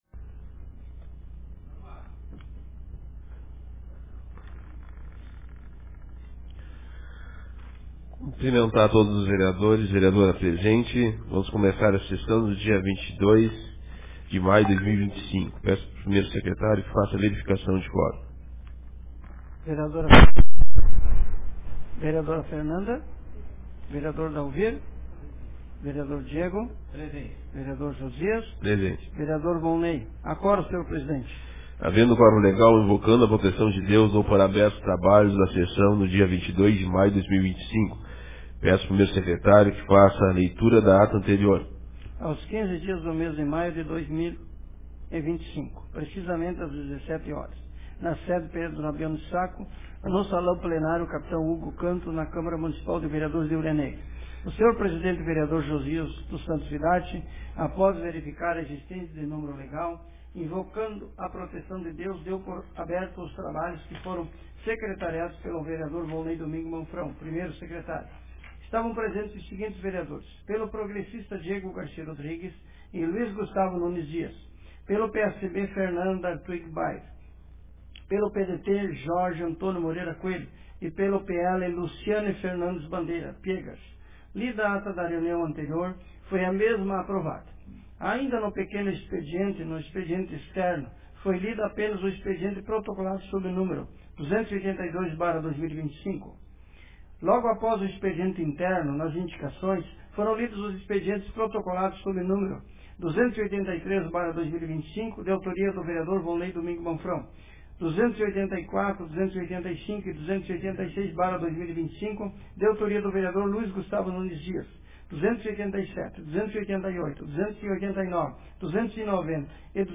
Sessão Ordinária da Câmara de Vereadores de Hulha Negra Data: 22 de maio de 2025